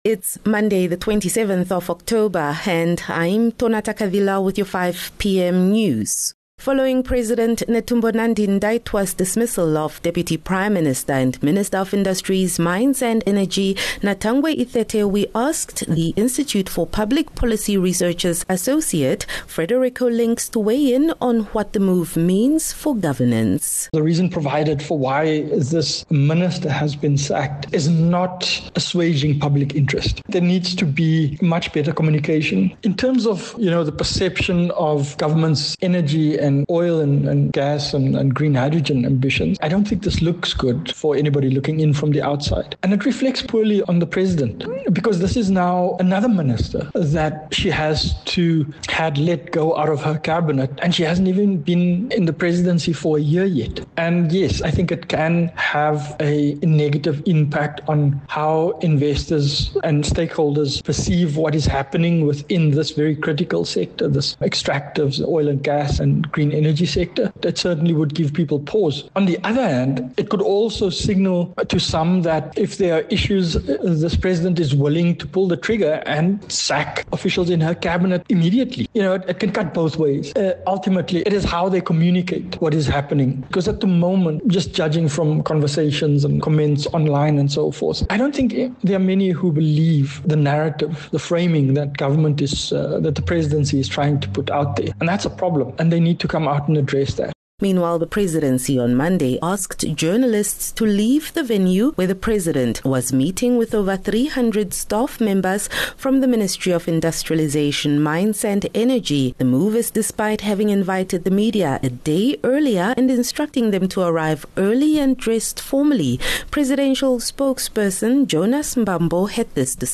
27 October - 5 pm news